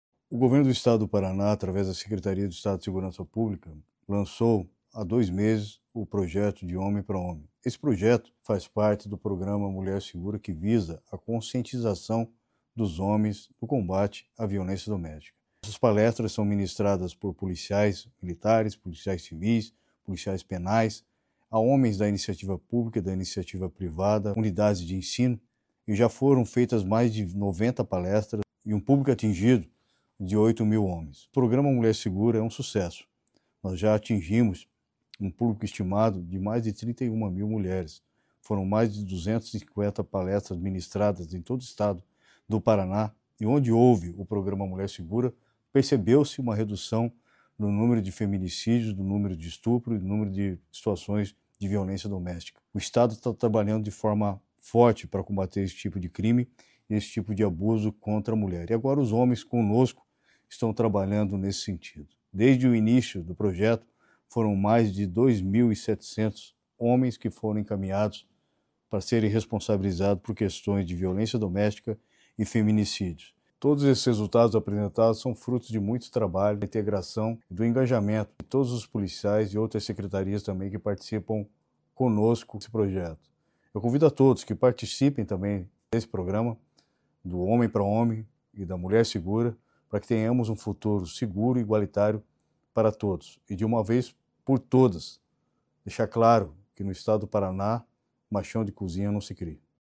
Sonora do secretário da Segurança Pública, Hudson Teixeira, sobre projeto de Homem para Homem